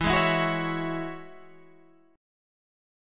Listen to E6add9 strummed